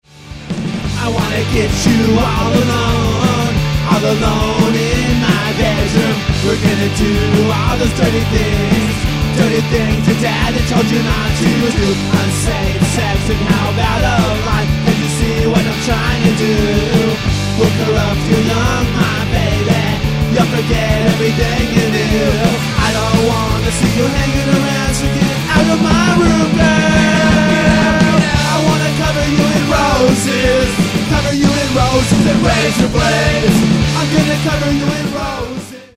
glam punk